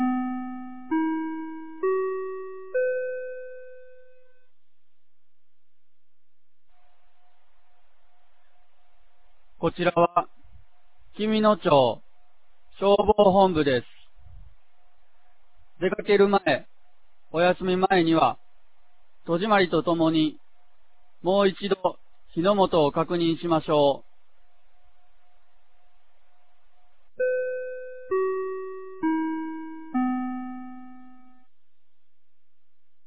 2026年03月14日 16時00分に、紀美野町より全地区へ放送がありました。